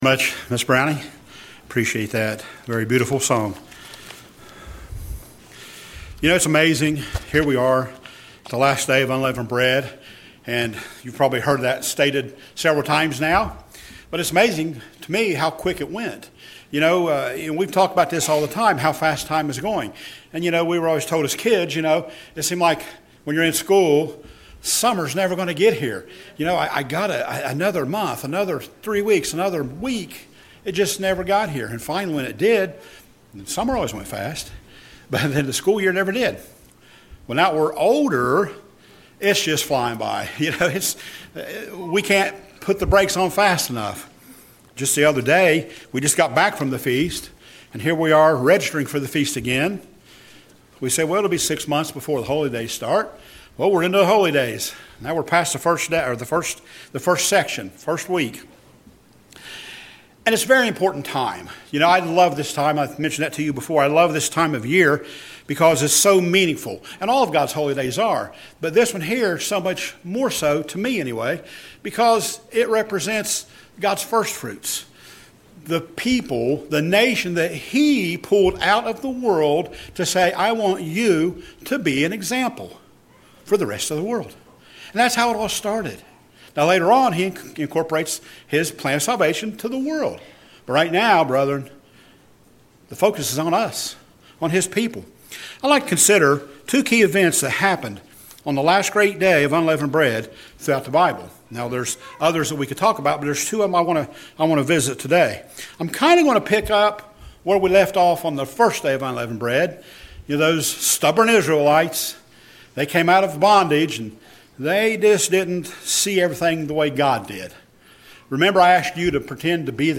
Sermons
Given in Portsmouth, OH Paintsville, KY